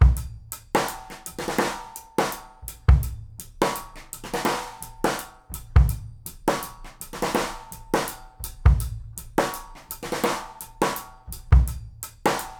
GROOVE 170GL.wav